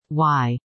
Y_female.mp3